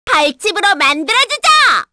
Hanus-Vox_Skill3_kr_b.wav